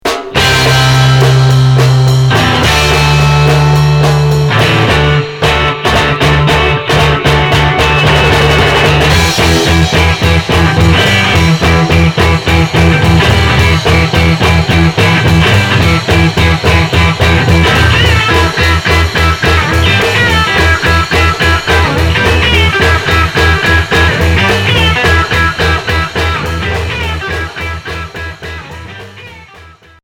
Garage Unique 45t